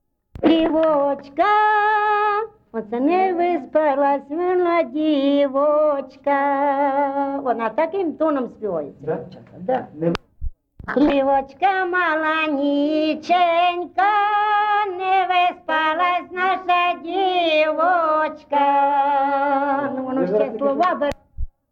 ЖанрПетрівчані
Місце записус. Писарівка, Золочівський район, Харківська обл., Україна, Слобожанщина